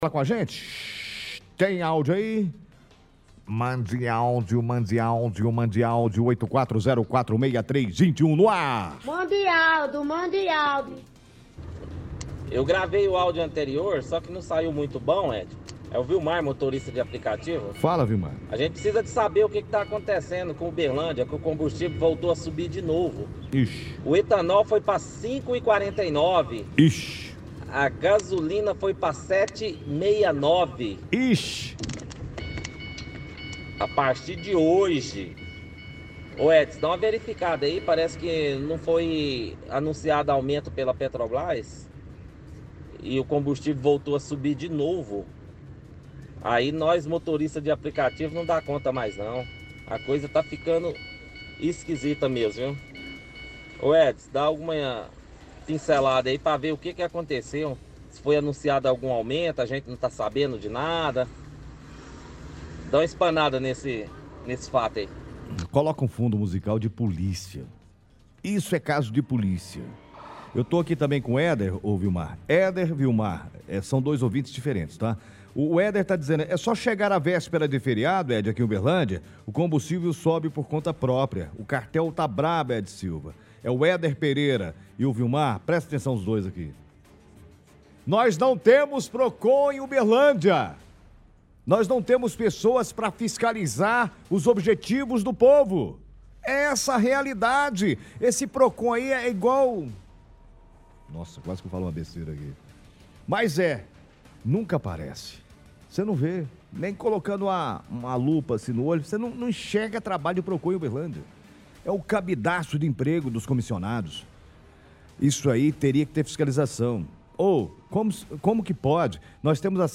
Ligação Ouvintes – Combustíveis Procon